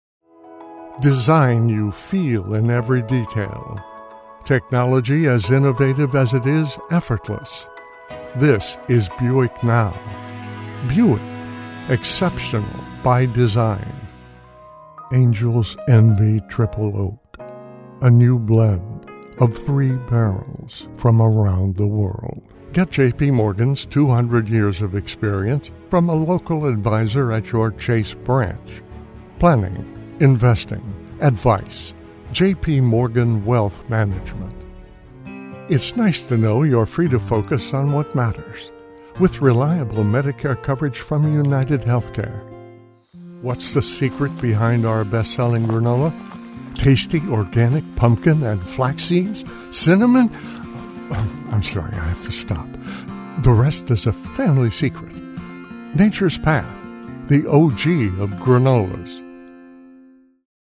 Commercial Narration Character Voiceovers
Commercial